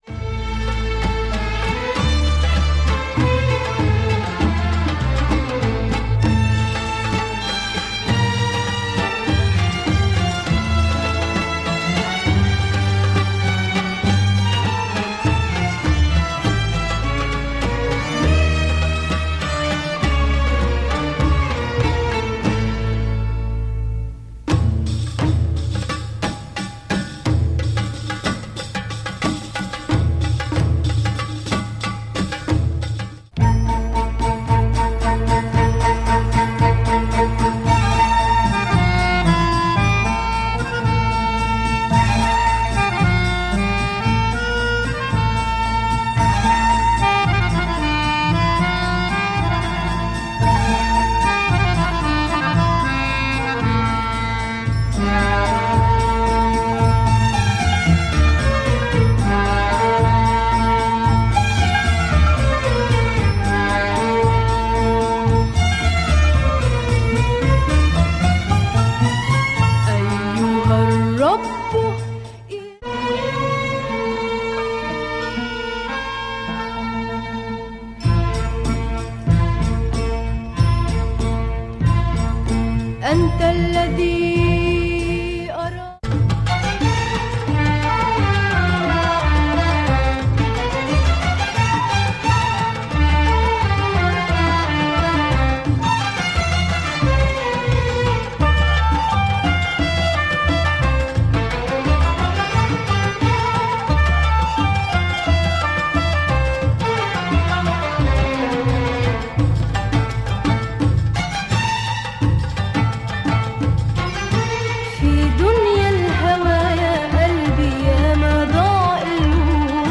Killer Lebanese beats on this one.